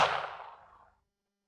Pistol Shot
A single pistol shot with sharp muzzle crack, brief echo, and subtle mechanical action
pistol-shot.mp3